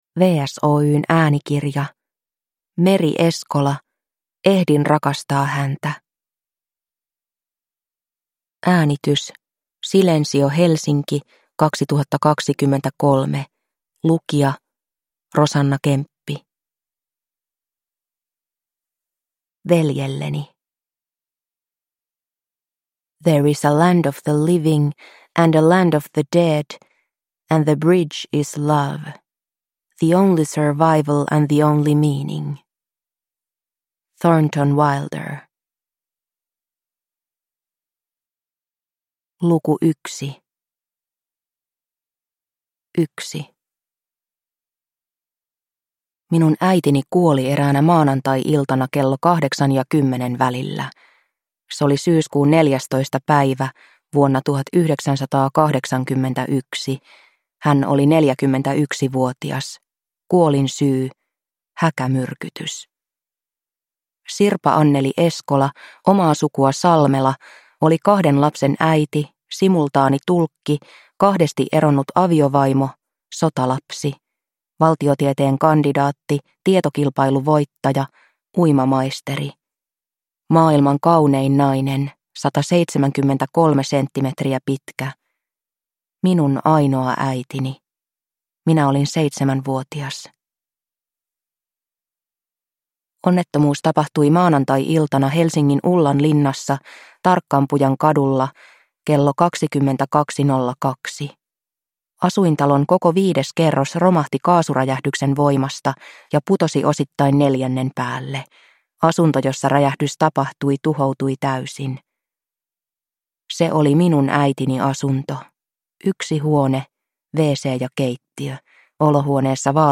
Ehdin rakastaa häntä – Ljudbok – Laddas ner